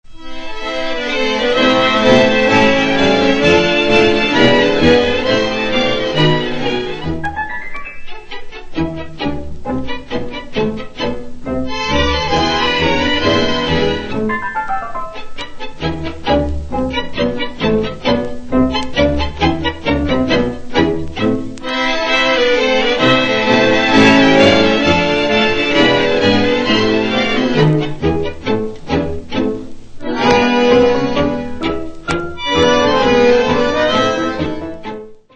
Klassische Tangos